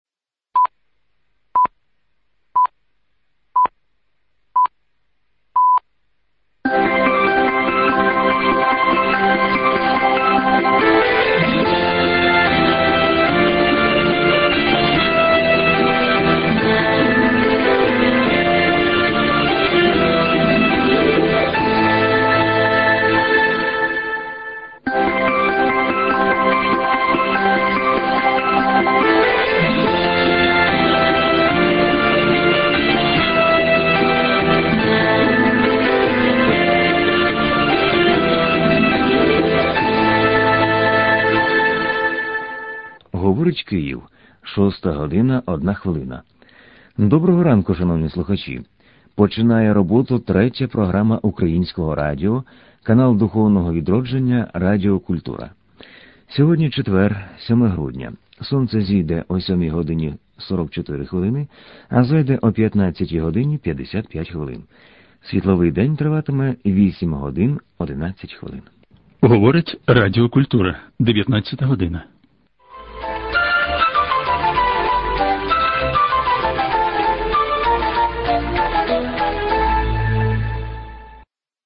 позивні 2001 р.